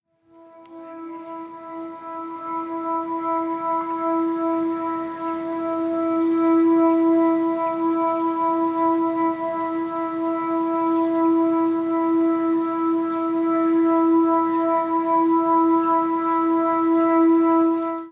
나도_모르게_사이렌소리.mp3